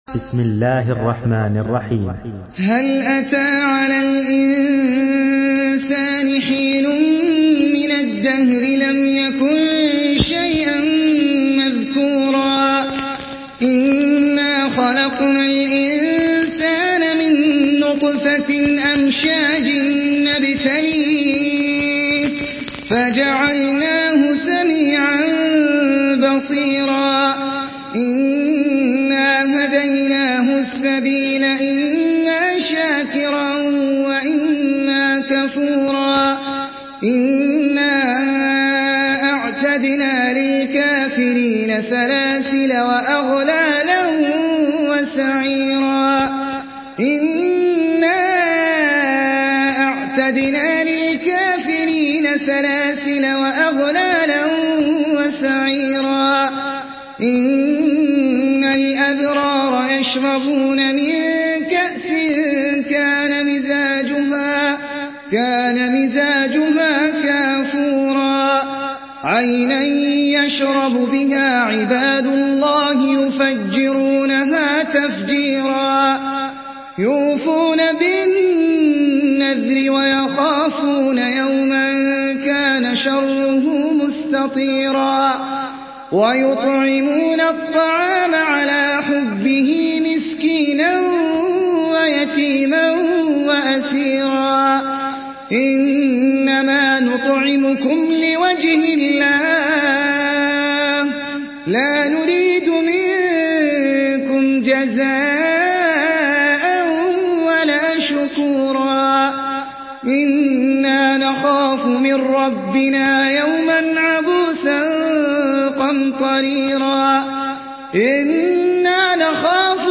Surah Al-Insan MP3 in the Voice of Ahmed Al Ajmi in Hafs Narration
Murattal